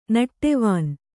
♪ naṭṭevān